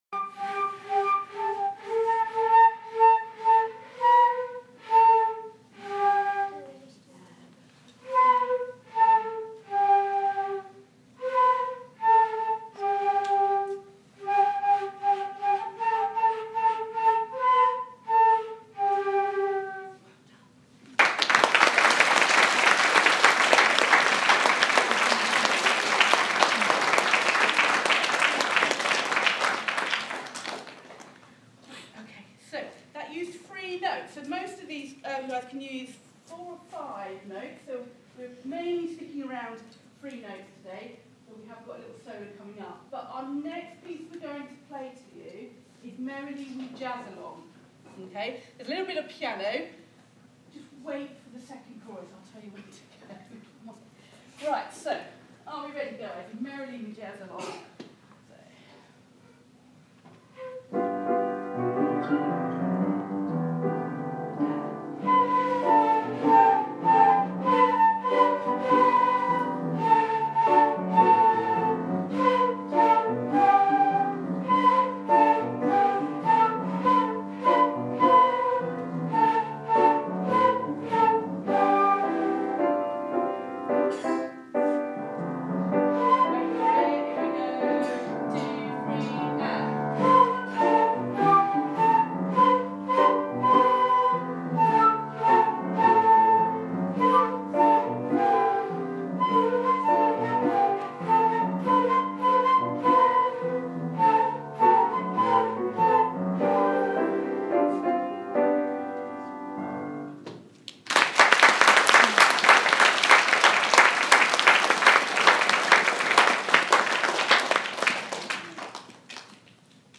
flutes